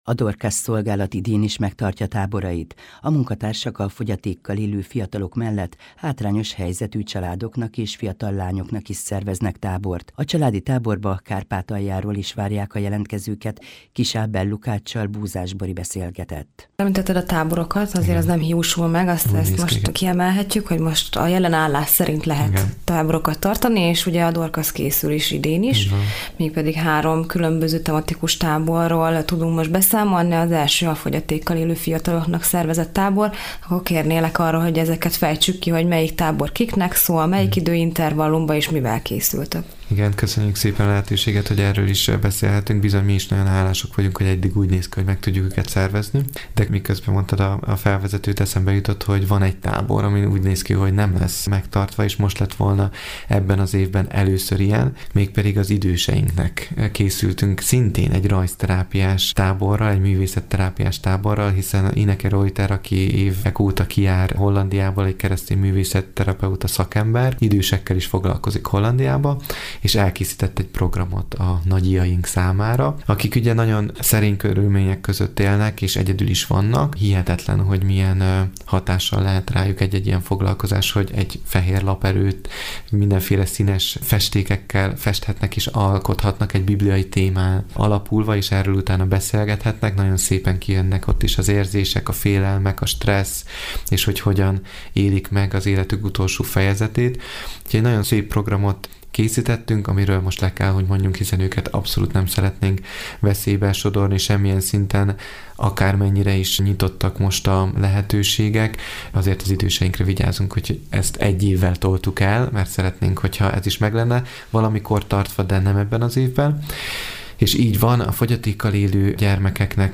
Európa Rádióban